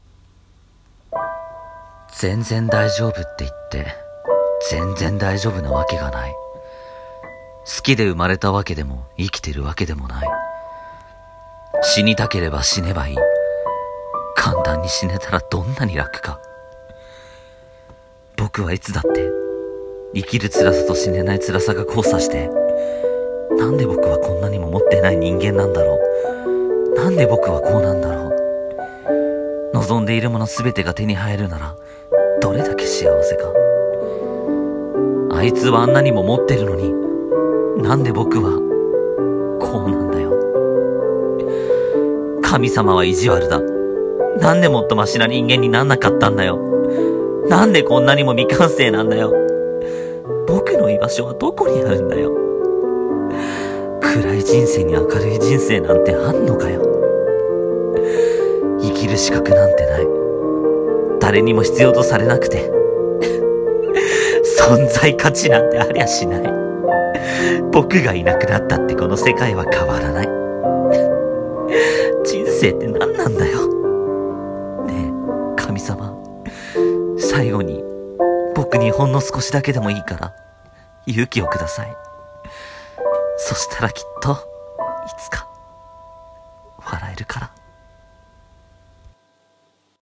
【声劇】勇気をください【一人声劇】